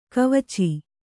♪ kavaci